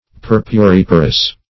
Search Result for " purpuriparous" : The Collaborative International Dictionary of English v.0.48: Purpuriparous \Pur`pu*rip"a*rous\, a. [L. purpura purple + parere to produce.]
purpuriparous.mp3